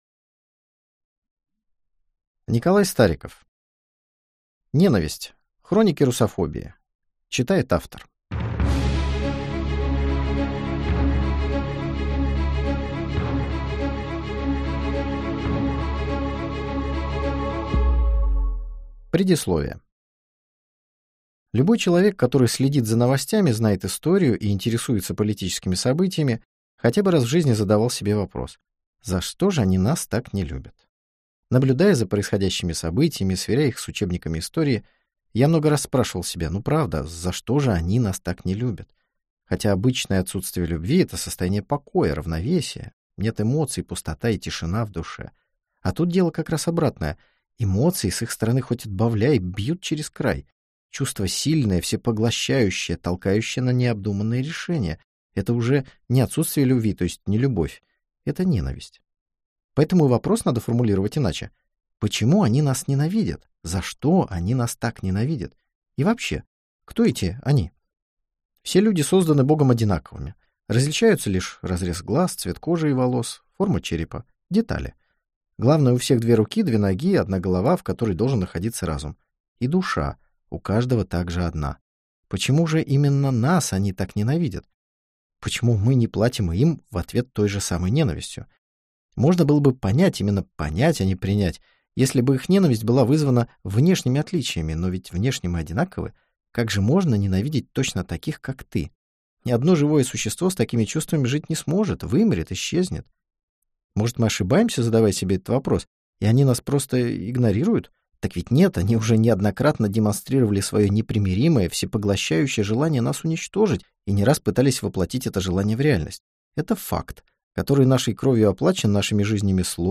Аудиокнига Ненависть. Хроники русофобии - купить, скачать и слушать онлайн | КнигоПоиск